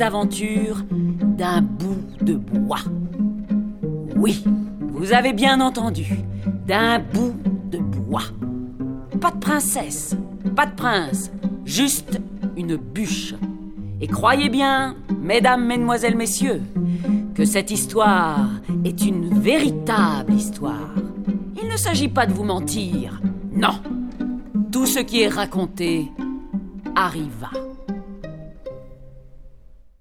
Livre CD
• Narrateur :
Elsa Lepoivre